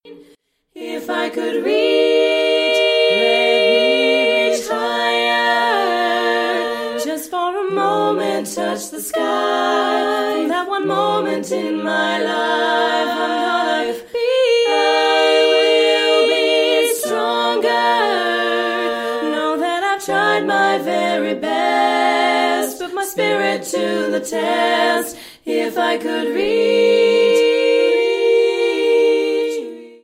inspirational pop song from 1996 Olympics